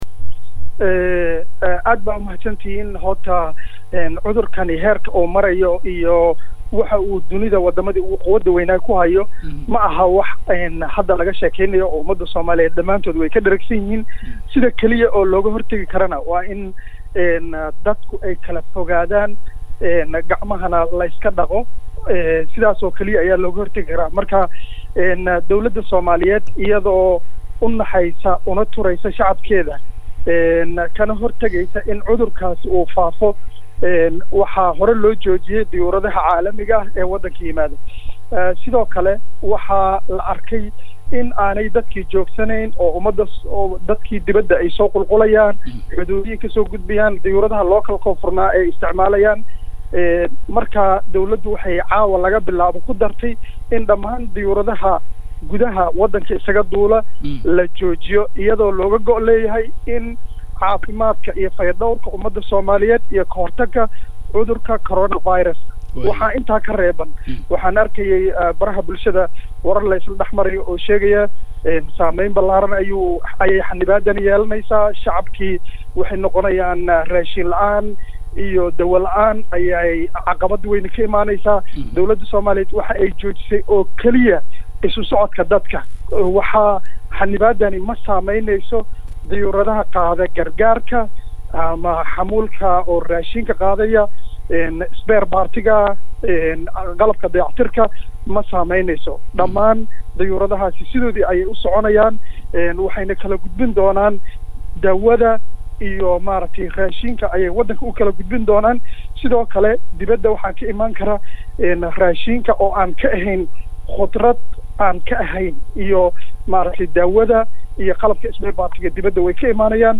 WAREYSI-MAAREEYAHA-HAYADDA-DUULISTA-.mp3